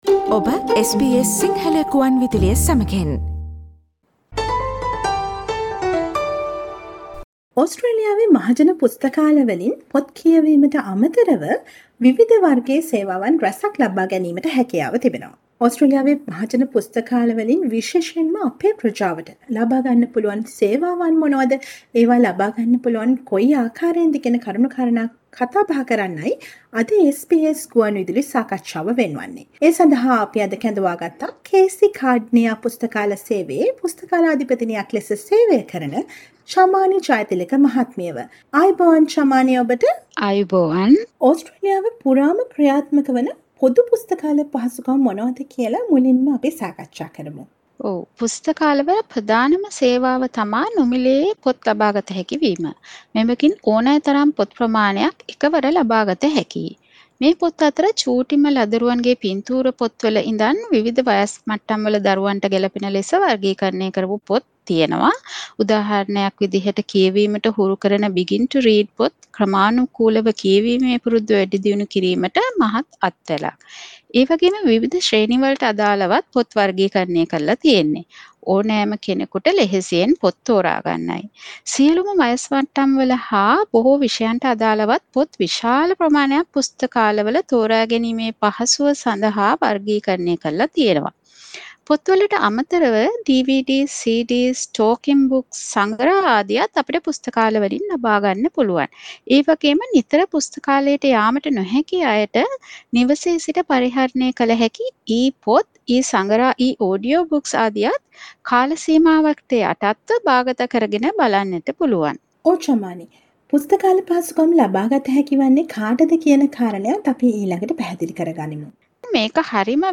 SBS සිංහල ගුවන්විදුලි සේවය මෙල්බර්න් සිට සිදු කළ සාකච්ඡාව